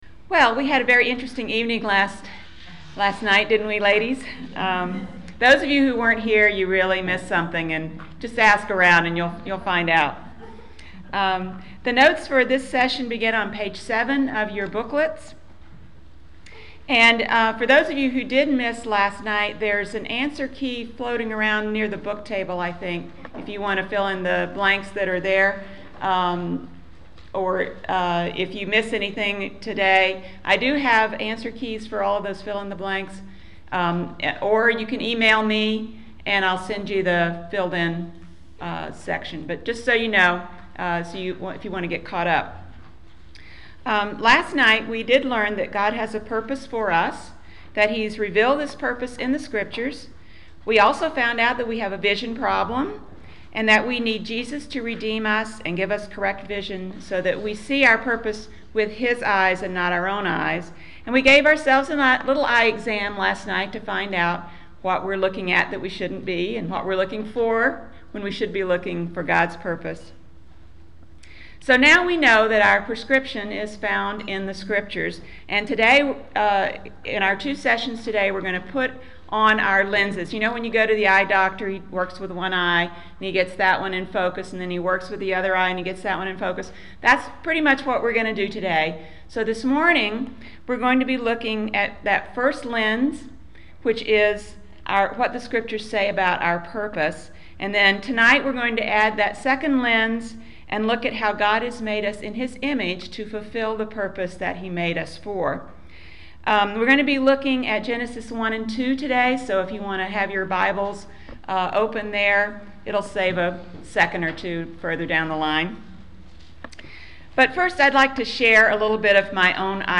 At the end of April I spoke at our Presbytery women's spring retreat on finding our purpose.